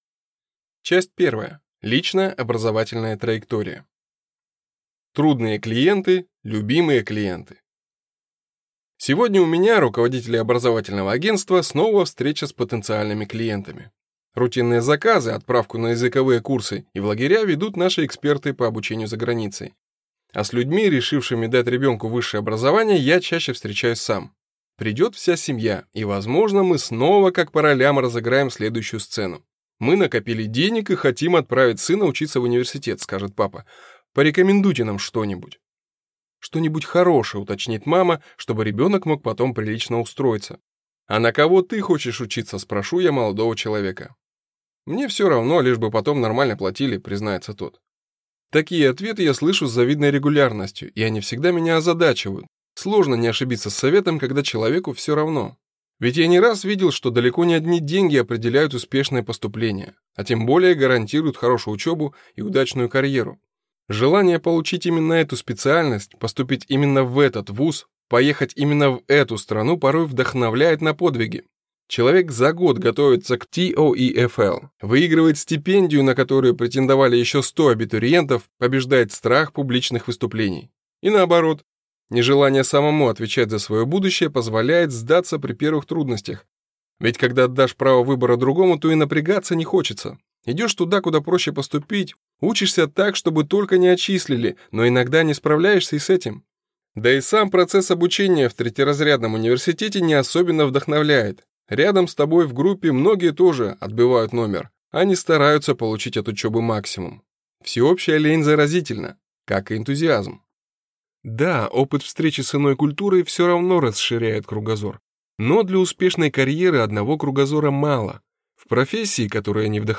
Аудиокнига Образование за границей. Полное руководство | Библиотека аудиокниг